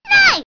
One of Princess Daisy's voice clips in Mario Kart DS